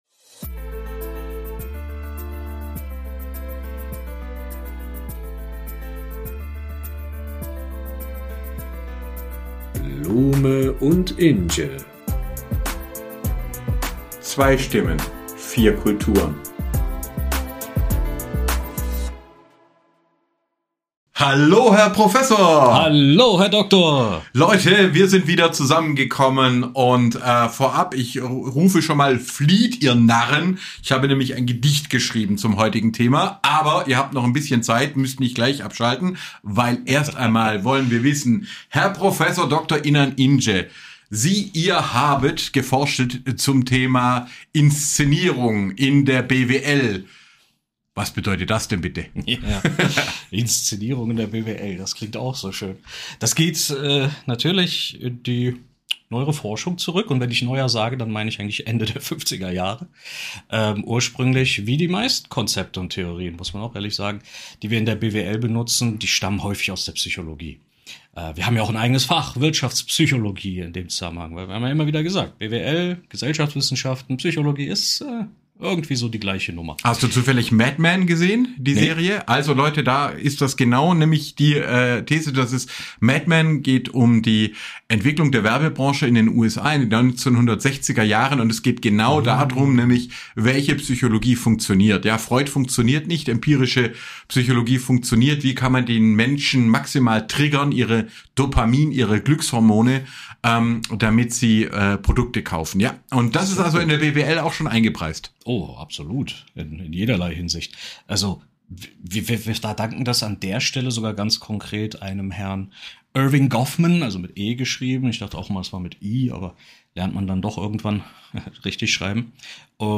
Applaus-Sound